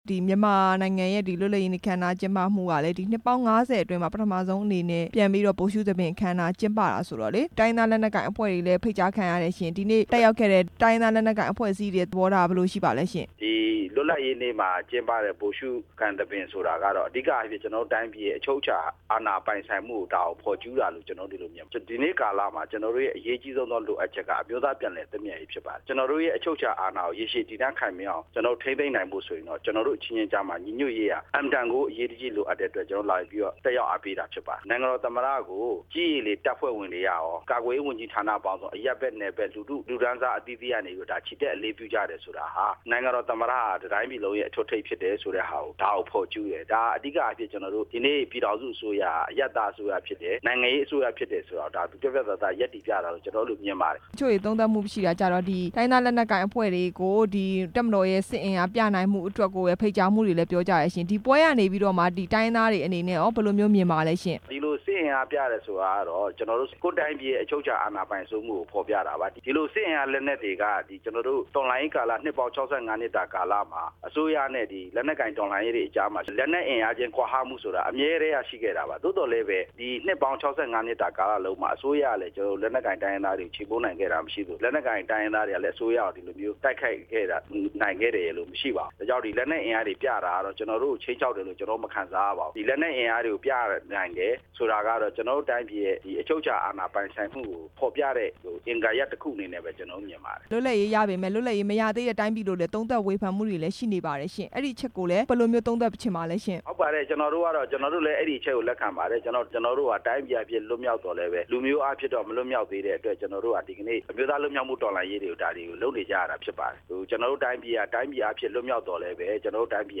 တိုင်းရင်းသား လက်နက်ကိုင် ၁၂ ဖဲ့ွနဲ့ သမ္မတ ဦးသိန်းစိန်တို့ တွေ့ဆုံမယ့်အကြောင်း မေးမြန်းချက်